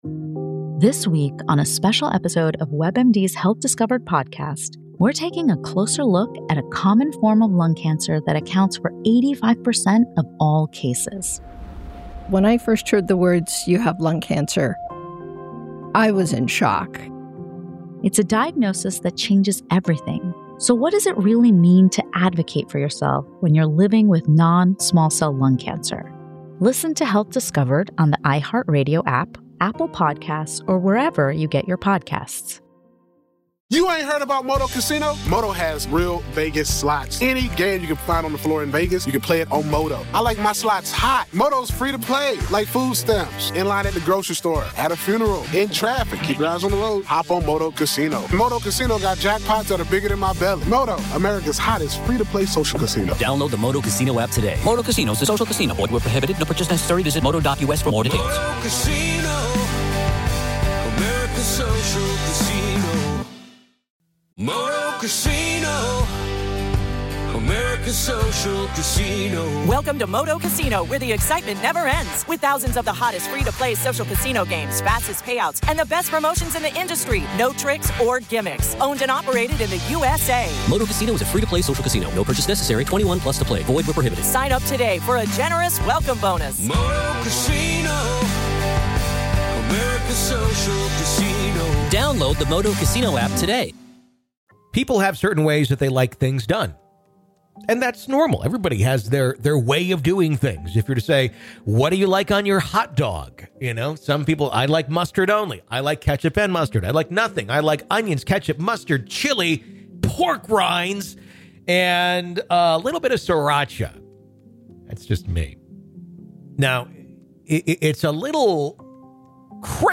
We hear the account of one man who hears from the dead on a regular basis.